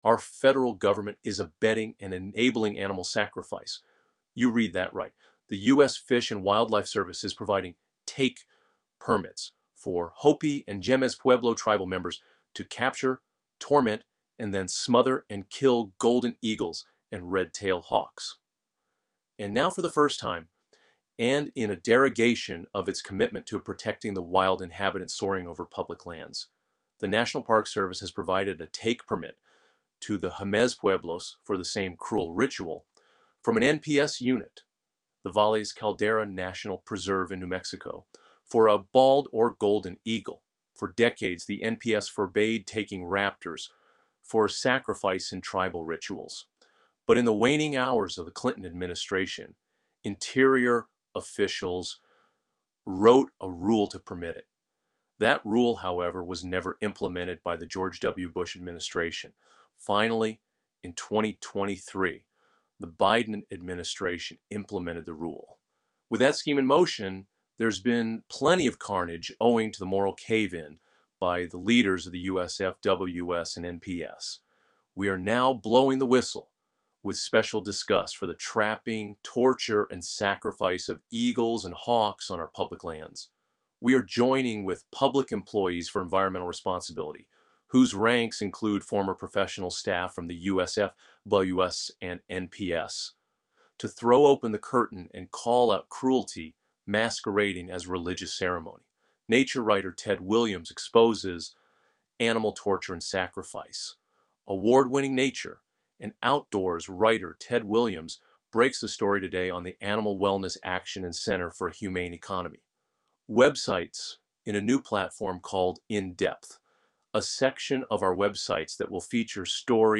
You can listen to an AI-generated reading of this story here: